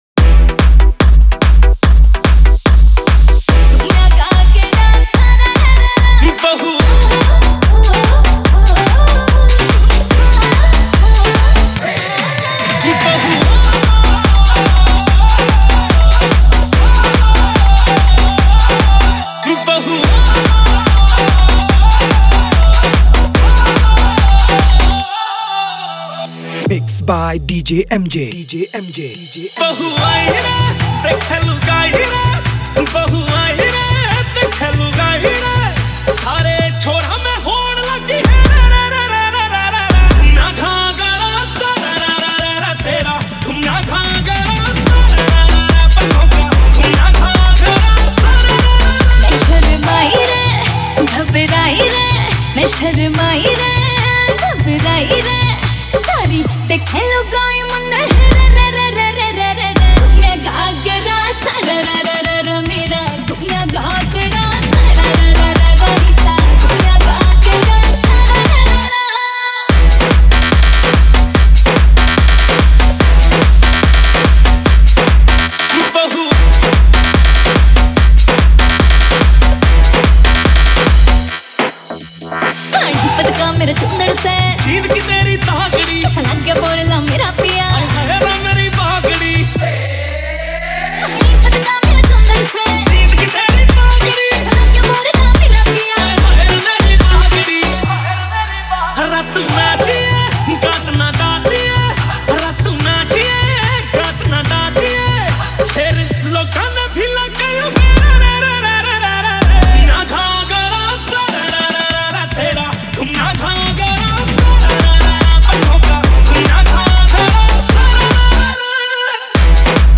Haryanvi DJ Remix Songs Latest DJ Remix Mp3 Song Download
Haryanvi DJ Remix Songs